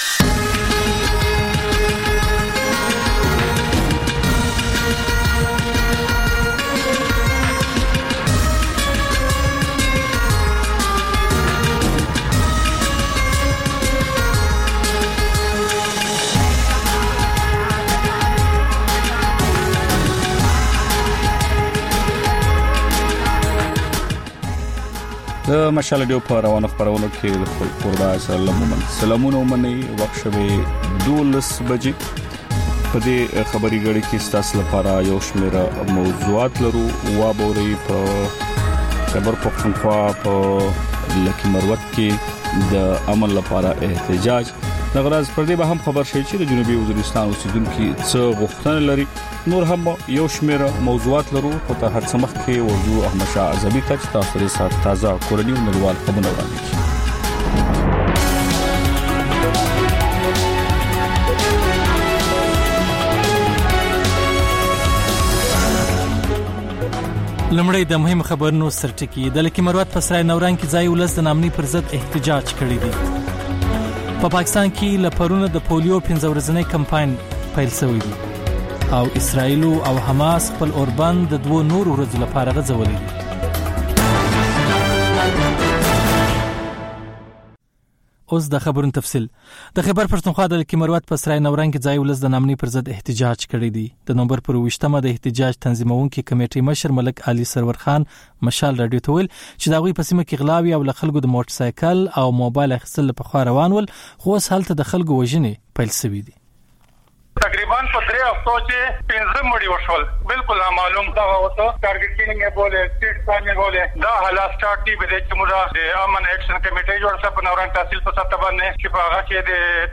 د مشال راډیو د نهه ساعته خپرونو لومړۍ خبري ګړۍ. په دې خپرونه کې تر خبرونو وروسته بېلا بېل سیمه ییز او نړیوال رپورټونه، شننې، مرکې، رسنیو ته کتنې، کلتوري او ټولنیز رپورټونه خپرېږي.